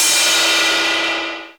RIDE 2.wav